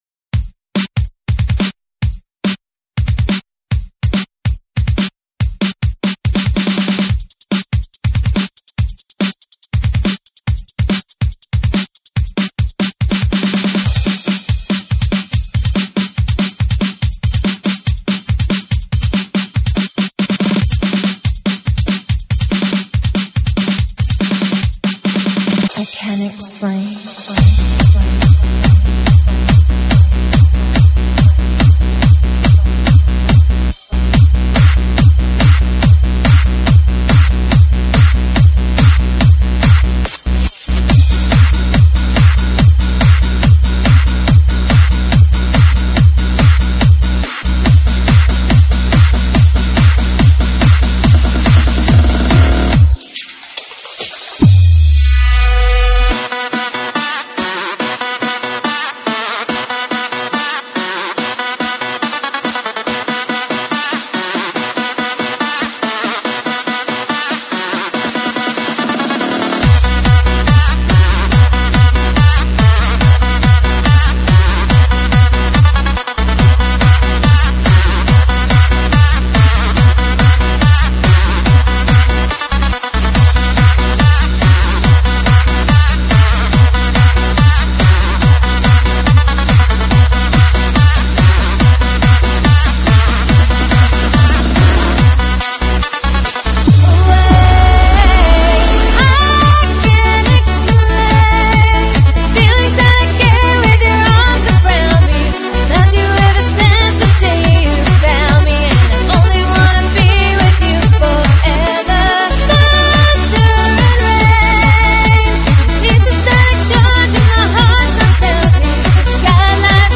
舞曲类别：新年喜庆